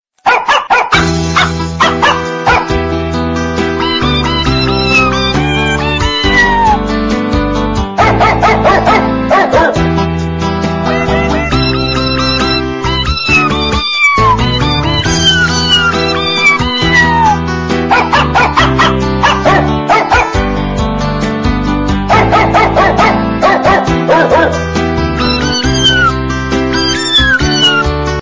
Cat Dog-sound-HIingtone